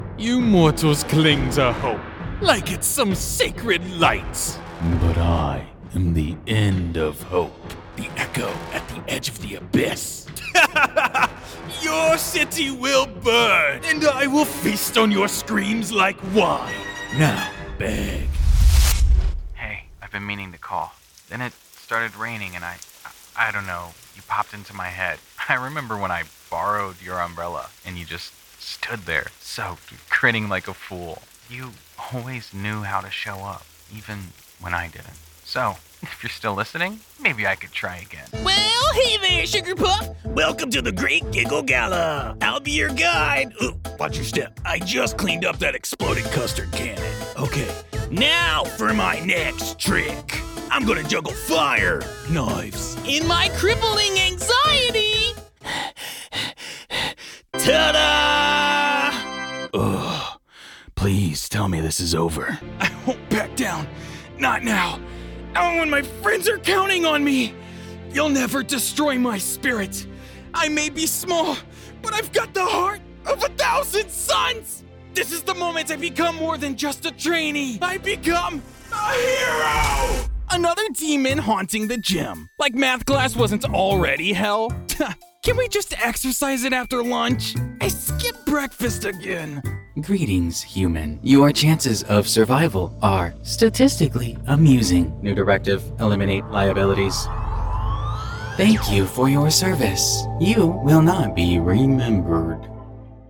Teenager, Young Adult, Adult, Mature Adult
They know when to pull back and when to let the moment land.
ANIMATION 🎬
broadcast level home studio